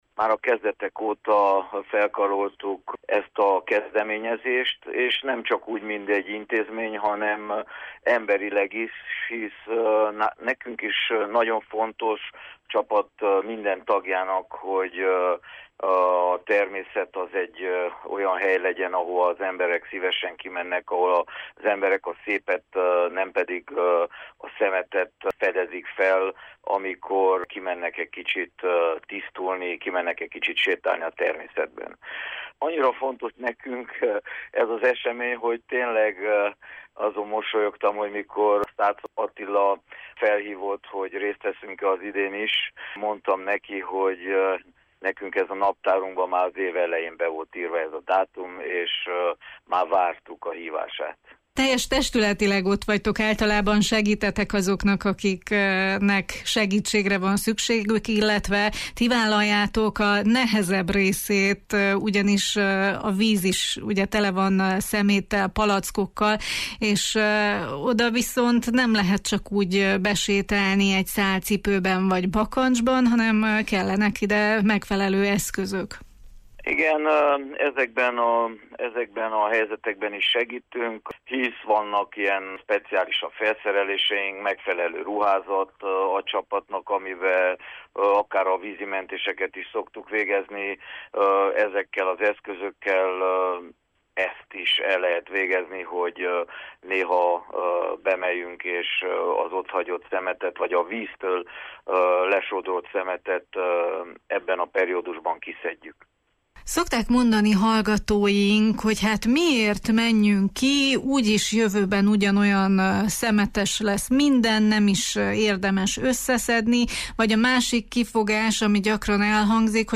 A következő percekben a Közösen Kitakarítjuk 2023-as kiadásának partnerintézményei válaszolnak arra a kérdésre, hogy miért fontos évről évre felkiáltójelet helyezni a szemétszedési akció mellé: